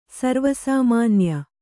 ♪ sarva sāmānya